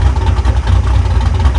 Index of /server/sound/vehicles/lwcars/quadbike
idle.wav